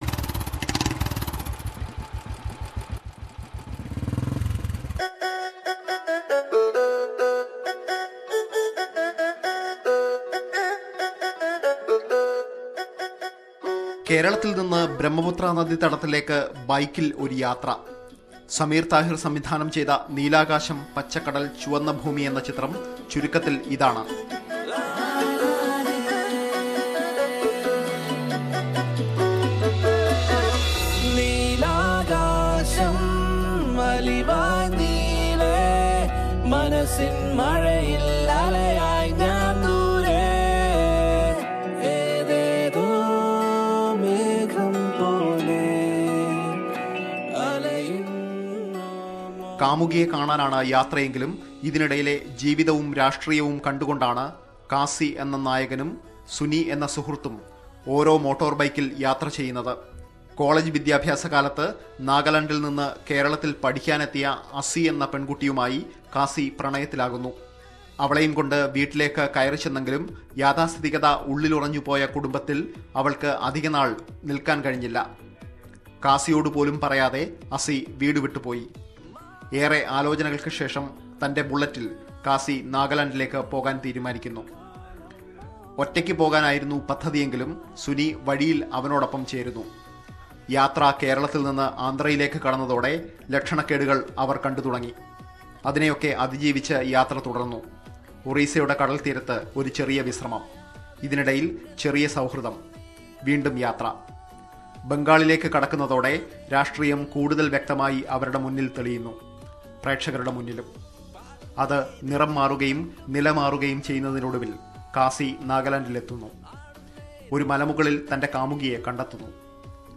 Movie Review: Neelakasham Pachakadal Chuvanna Bhoomi